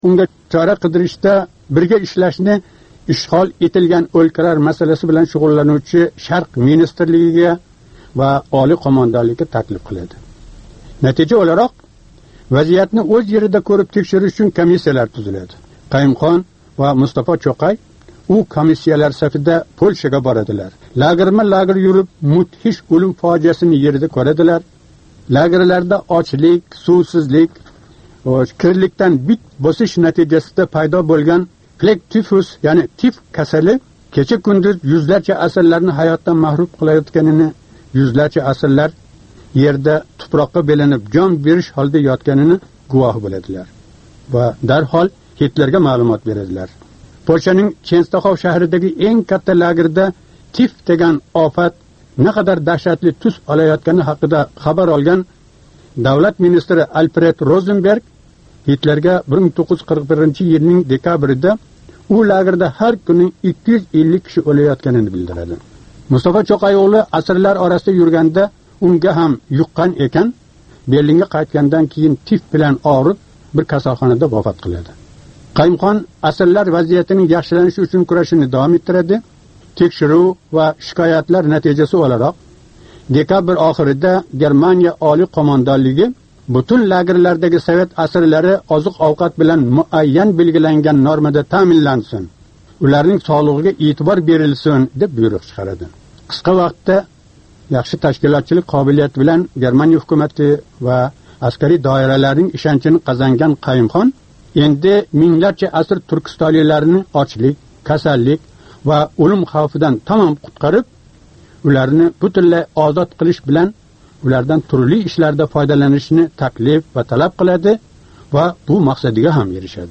"7 кун - Ўзбекистон": Ҳафта давомида Ўзбекистон сиëсий¸ иқтисодий-ижтимоий ҳаëти¸ қолаверса мамлакатдаги инсон ҳуқуқлари ва демократия вазияти билан боғлиқ долзарб воқеалардан бехабар қолган бўлсангиз "7 кун - Ўзбекистон" ҳафталик радиожурналимизни тинглаб боринг. Бу туркум ҳафтанинг энг муҳим воқеалари калейдоскопидир!